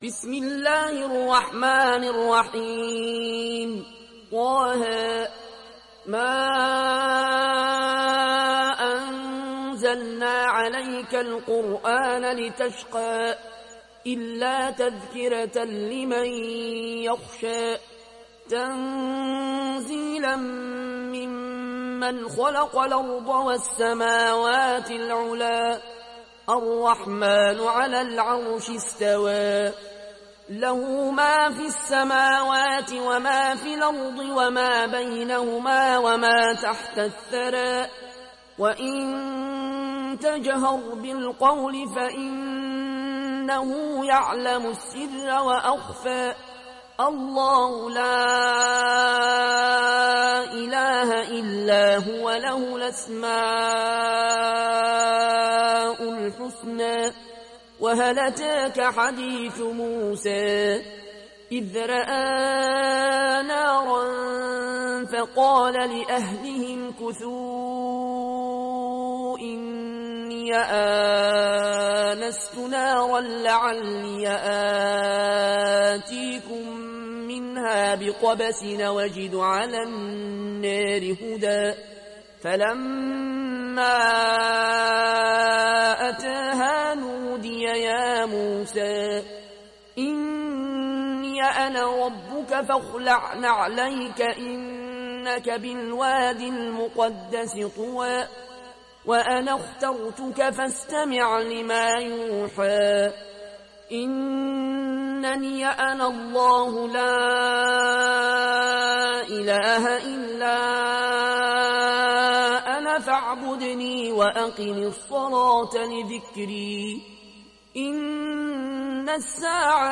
Surat Taha mp3 Download Al Ayoune Al Koshi (Riwayat Warsh)